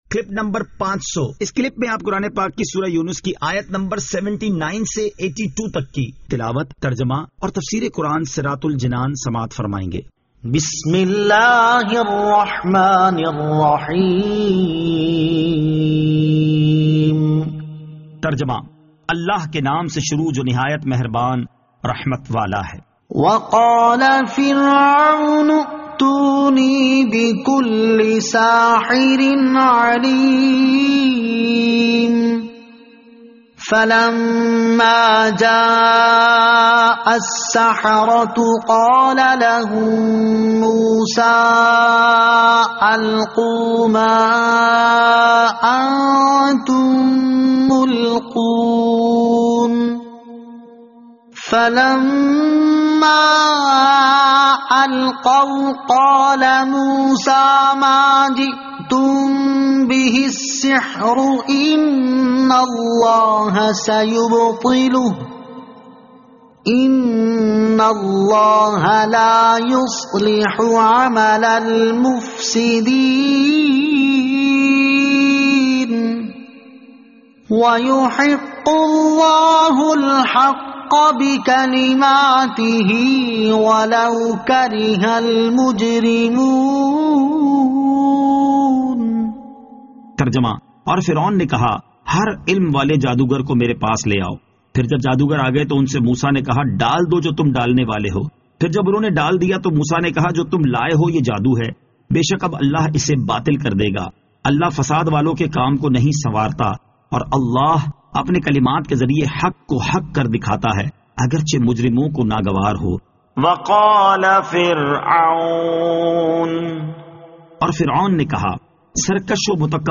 Surah Yunus Ayat 79 To 82 Tilawat , Tarjama , Tafseer